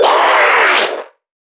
NPC_Creatures_Vocalisations_Infected [18].wav